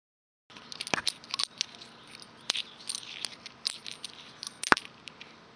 金属铿锵
描述：多金属叮当声
Tag: 光泽 金属 金属 咆哮